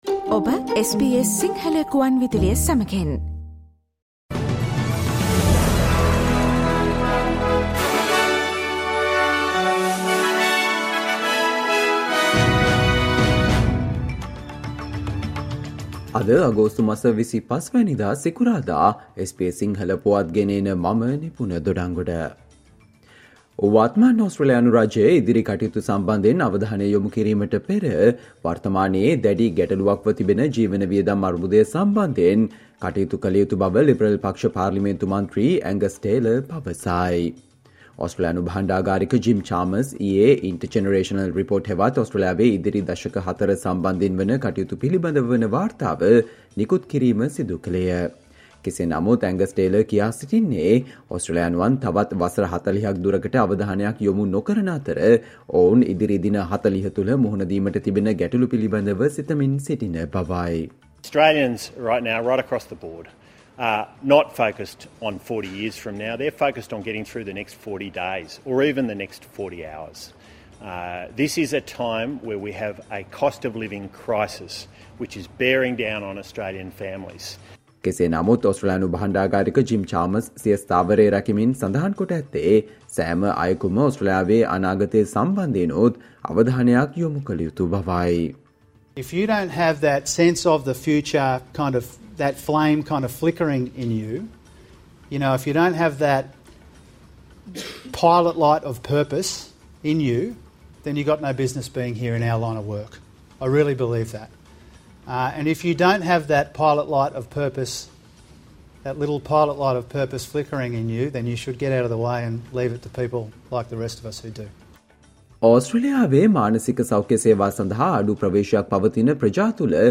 Australia news in Sinhala, foreign and sports news in brief - listen, today - Friday 25 July 2023 SBS Radio News